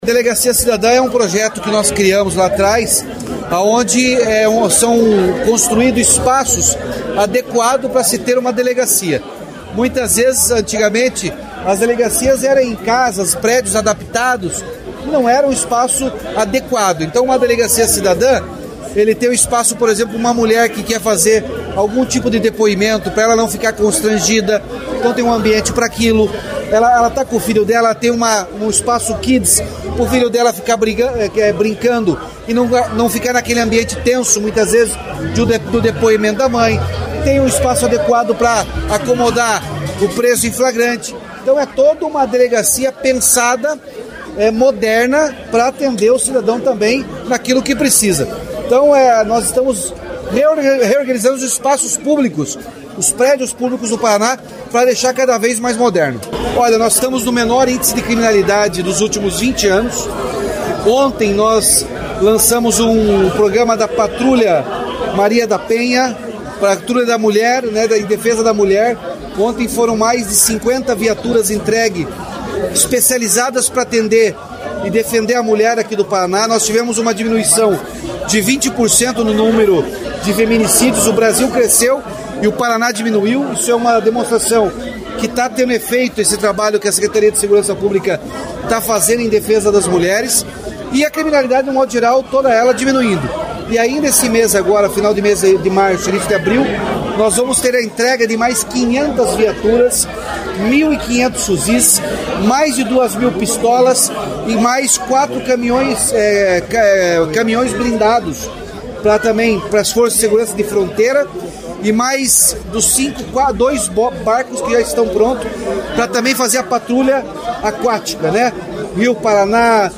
Sonora do governador Ratinho Junior sobre a Delegacia Cidadã de Cianorte